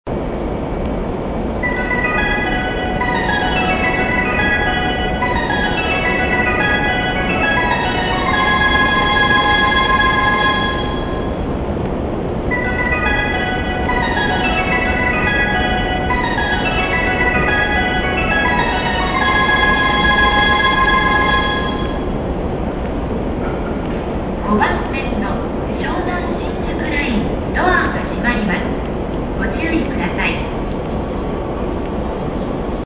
湘南新宿ライン発車時特快高崎行きの発車時の音声です。
発車放送に「の」がついている駅では、発車放送が詳細型になっています。